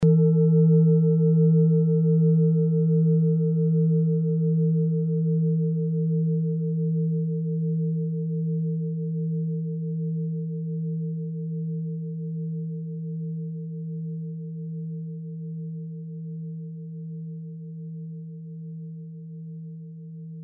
Klangschale Orissa Nr.29
Klangschale-Gewicht: 850g
Klangschale-Durchmesser: 17,8cm
Sie ist neu und wurde gezielt nach altem 7-Metalle-Rezept in Handarbeit gezogen und gehämmert.
Die Frequenz des Jupiters liegt bei 183,58 Hz und dessen tieferen und höheren Oktaven. In unserer Tonleiter ist das in der Nähe vom "Fis".
klangschale-orissa-29.mp3